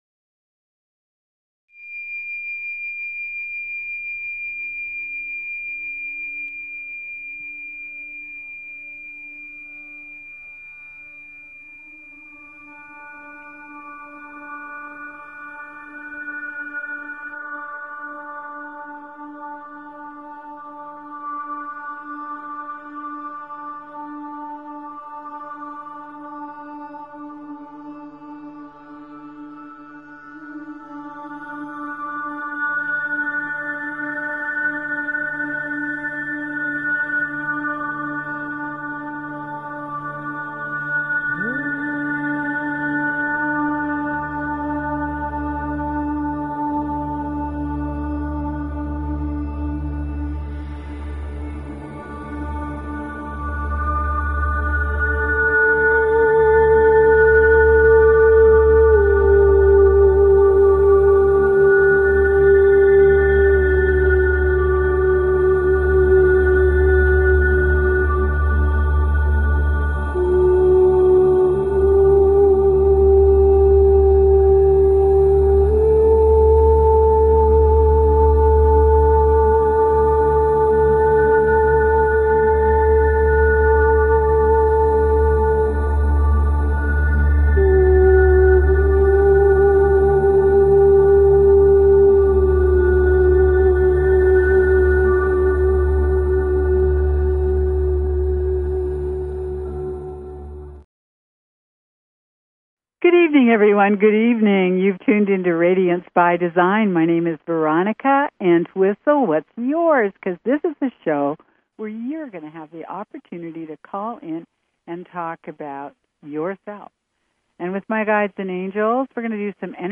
Talk Show Episode, Audio Podcast, Radiance_by_Design and Courtesy of BBS Radio on , show guests , about , categorized as
The show offers you a much needed spiritual tune up – gives you the means to hold your own as you engage the crazy dynamics that occupy our ever changing planet. Radiance By Design is specifically tailored to the energies of each week and your calls dictate our on air discussions. Together we explore multi-dimensional realities, healing through energy structures, chakra tuning, the complexities